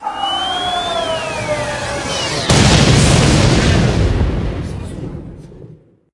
meteor_02.ogg